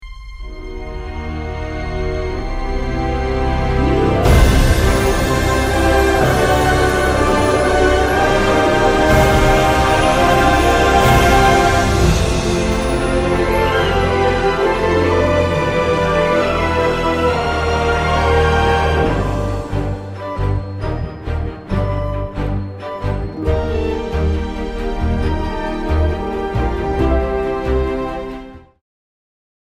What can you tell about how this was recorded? Fade-out added